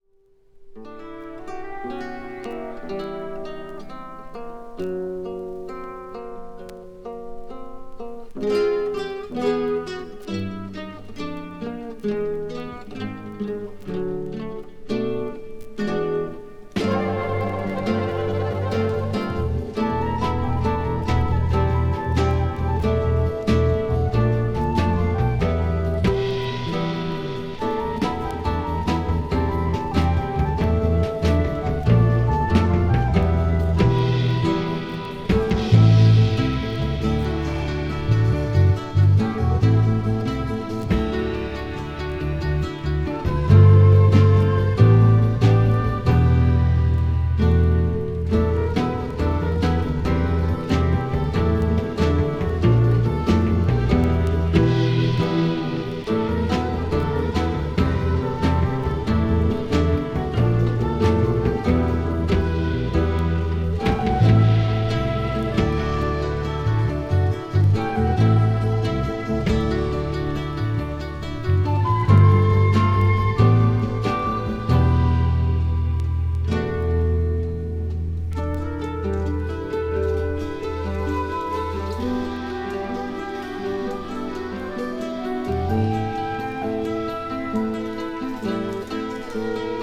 media : EX/EX(わずかにチリノイズが入る箇所あり)
jazz rock   progressive rock   psychedelic rock